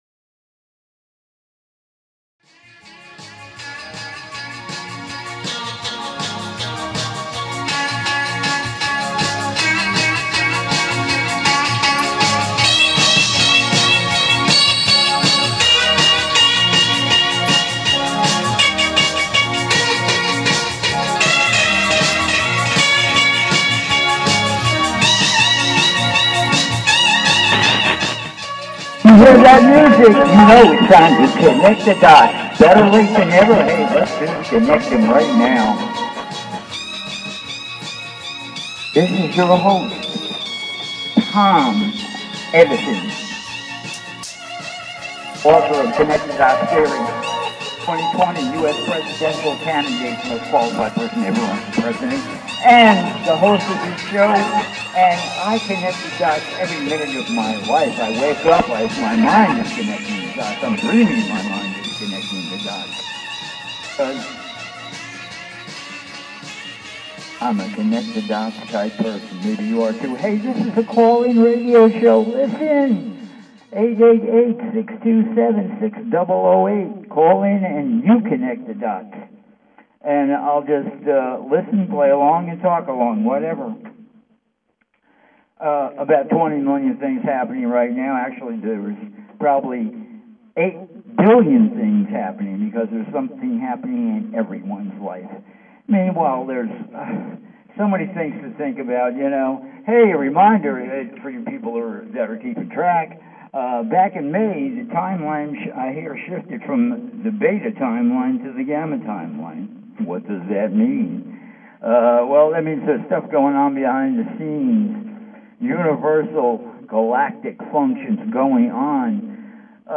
"CONNECT THE DOTS" is a call in radio talk show, where I share my knowledge of the metaphysical, plus ongoing conspiracies, plus the evolution of planet earth - spiritual info - et involvement - politics - crystals - etc.